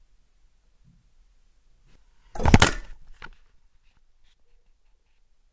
Cool clatter Sound
Duration - 3 s Environment - Inside very small bathroom fully tiled. Description - Stainless Steel clatters hand impact and recorder device.